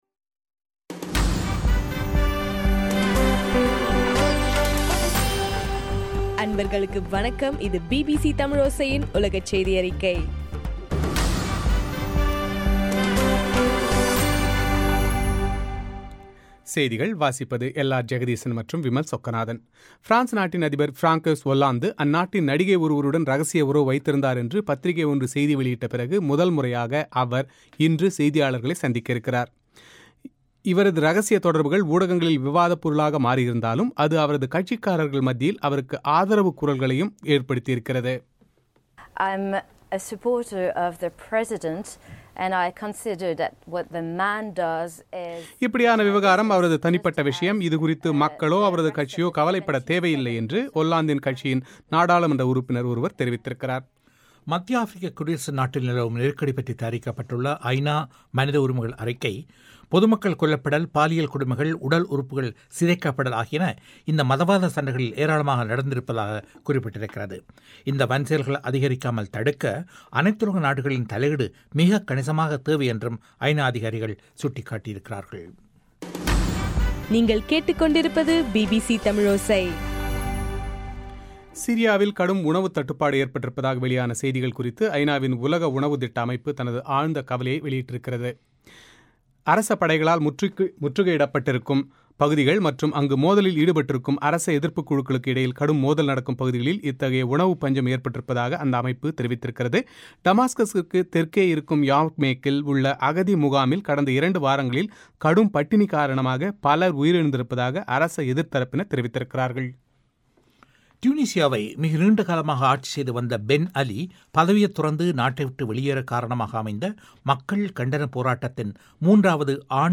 பிபிசி தமிழோசையின் உலகச் செய்தியறிக்கை ஜனவரி 14